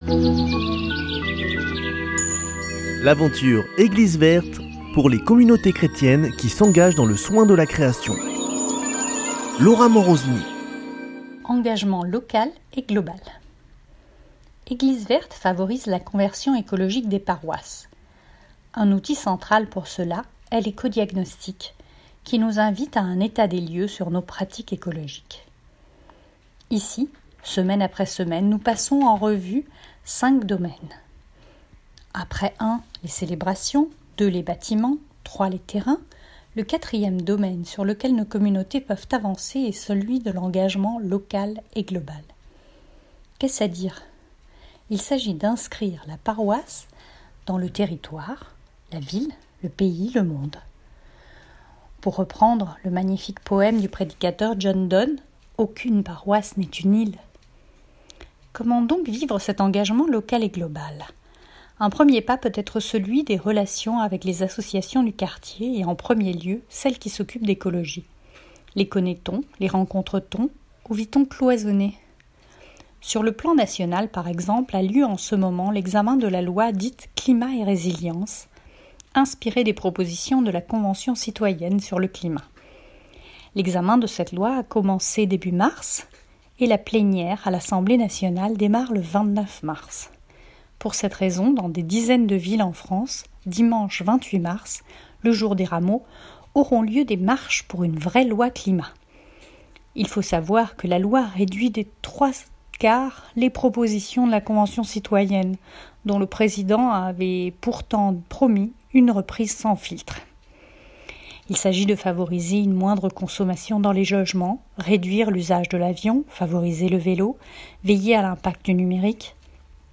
Chroniqueuse